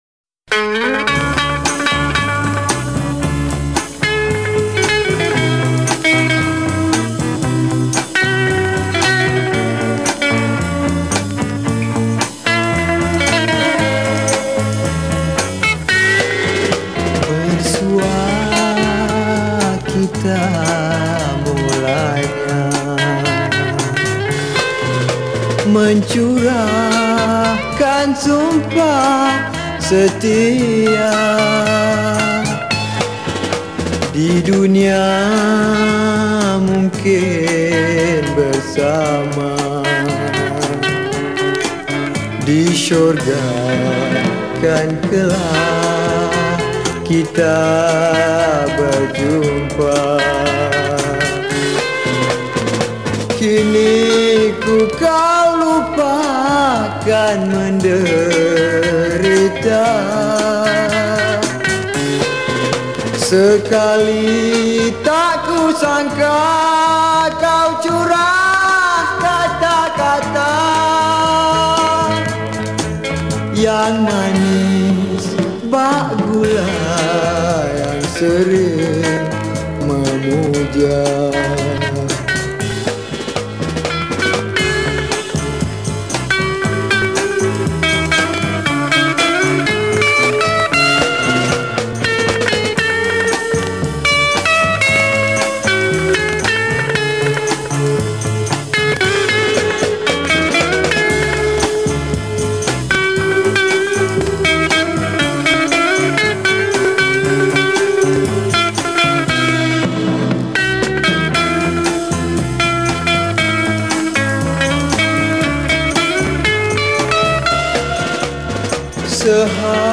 Pop Yeh Yeh
Malay Song